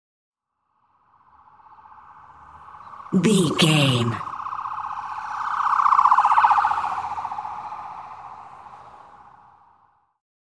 Ambulance Ext Passby Stress Siren
Sound Effects
urban
chaotic
emergency